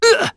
Zafir-Vox_Damage_kr_01.wav